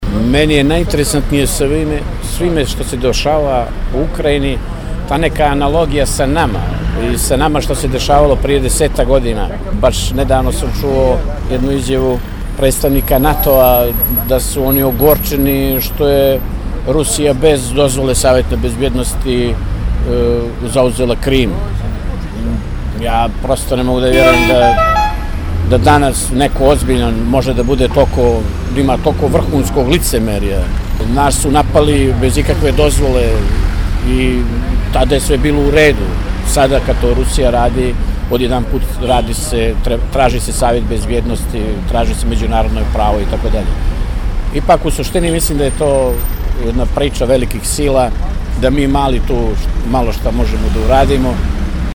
Pitali smo građane na ulicama gradova Crne Gore, Srbije i Bosne i Hercegovine šta misle o krizi u Ukrajini.